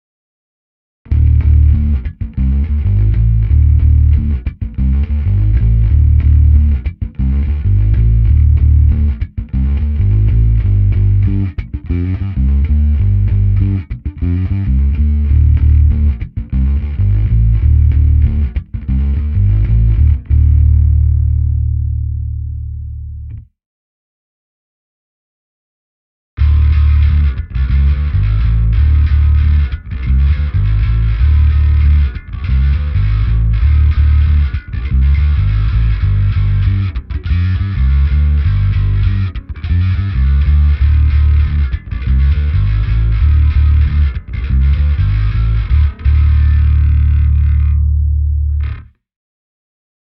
Zkreslený zvuk jsem naladil tak, aby se co nejvíce přiblížil mému oblíbenému charakteru, který mi poskytuje Darkglass Microtubes X Ultra. Pro srovnání tedy uvádím ukázku Photonu a mého používaného zvuku s Xkem.